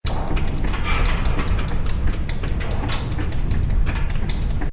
CHQ_FACT_gears_turning.ogg